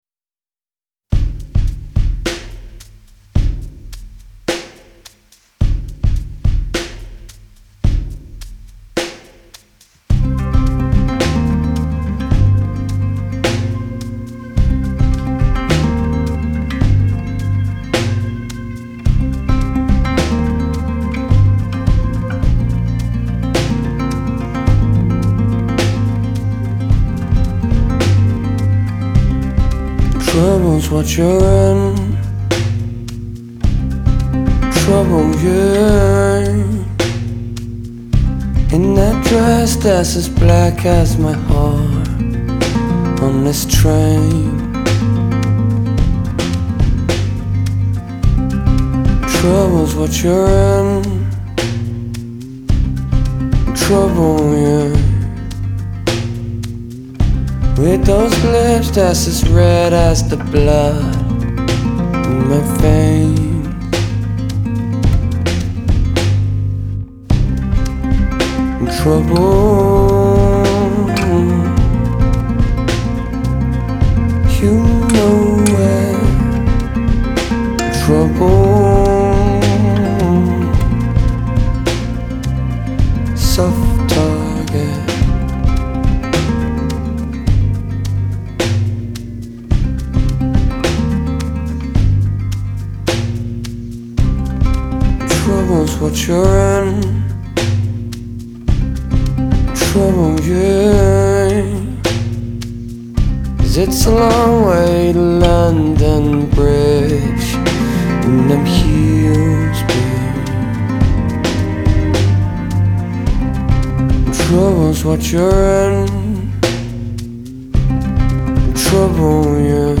Жанр: folk